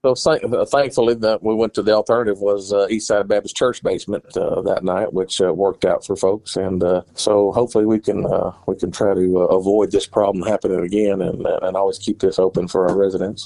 Judge Litty says they were fortunate to have a backup shelter, but wants to ensure the Courthouse will always be open as a shelter when needed.